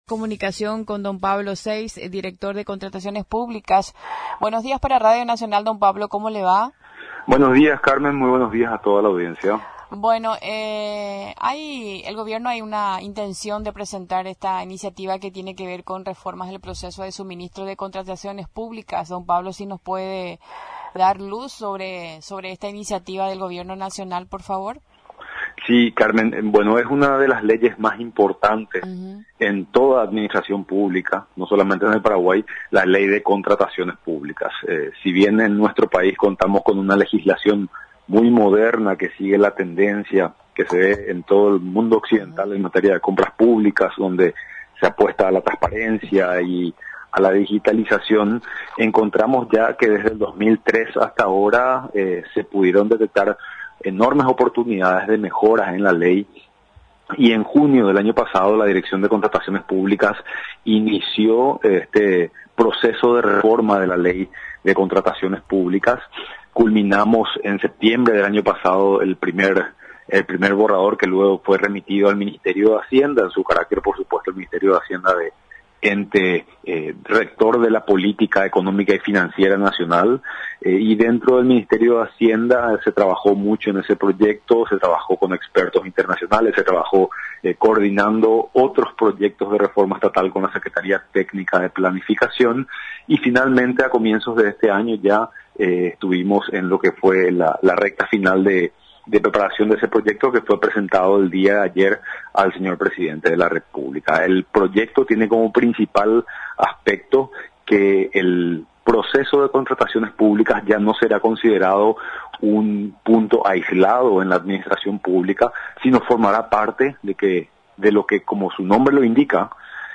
Algunas de las justificaciones del Proyecto de ley de suministros y contrataciones públicas presentado al Ejecutivo. Los detalles fueron facilitados por el director de Contrataciones Públicas, Pablo Seitz, entrevistado por Radio Nacional del Paraguay.